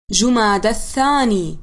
No. Name Arabic Pronunciation
jumadi-al-thani.mp3